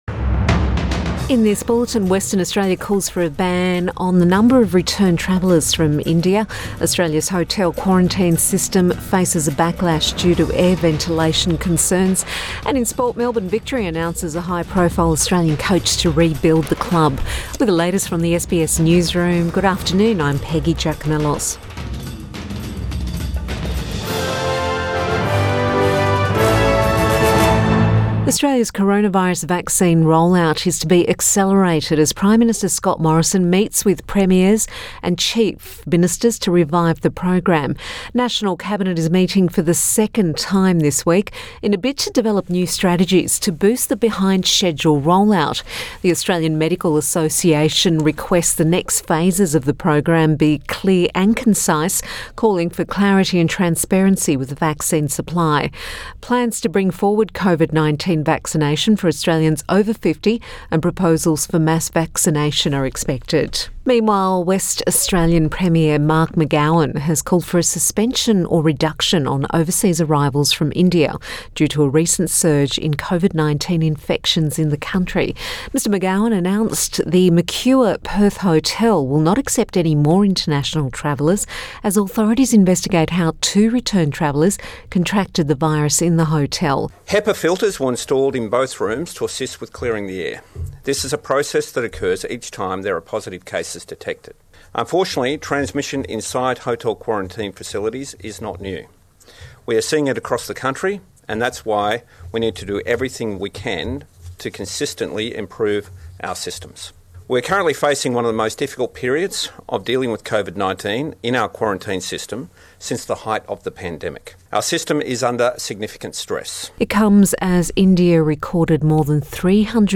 PM bulletin 22 April 2021